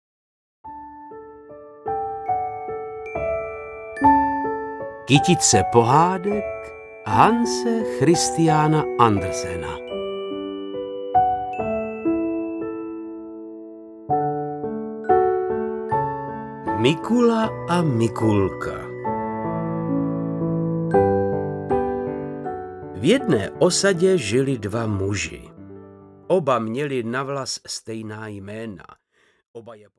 Mikula a Mikulka audiokniha
Ukázka z knihy
• InterpretVáclav Knop